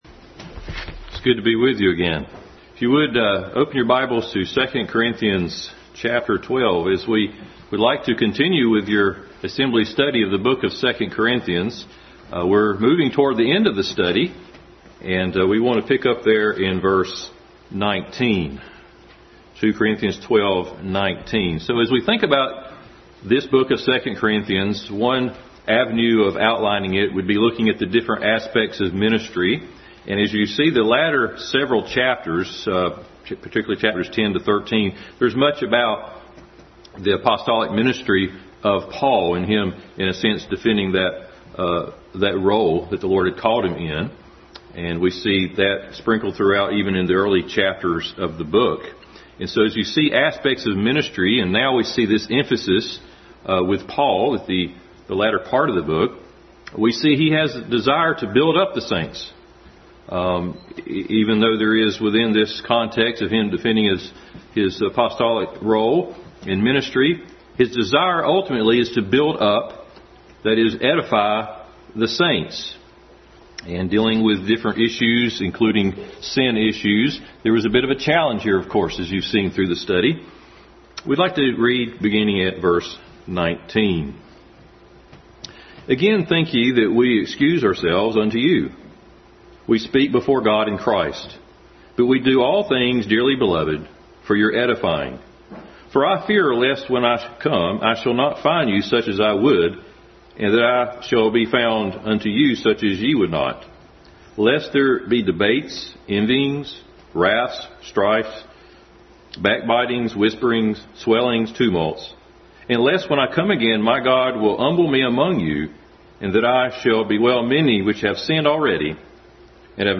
Adult Sunday School Class concluding study in 2 Corinthians.